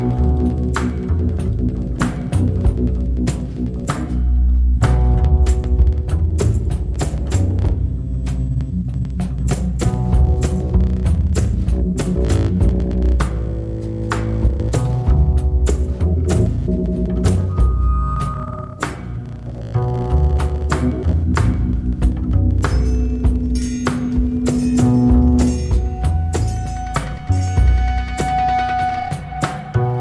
guitarrista
contrabaixista
baterista